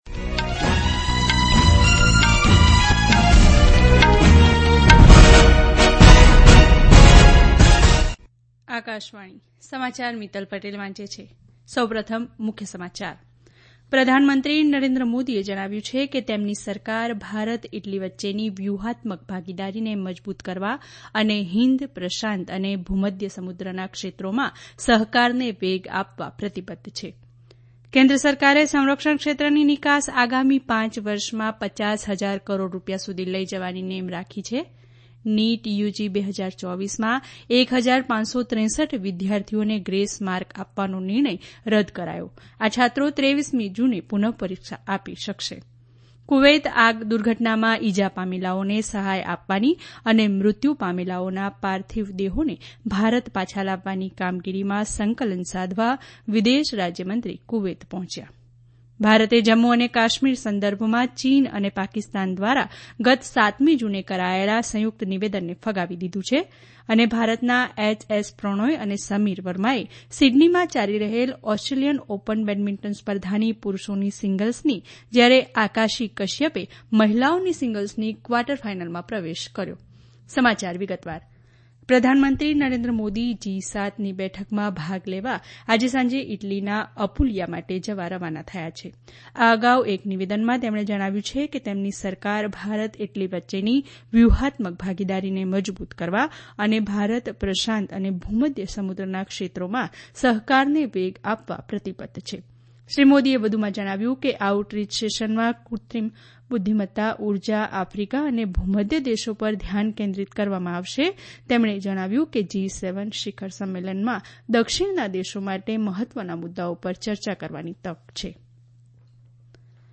Access Bulletins From Cities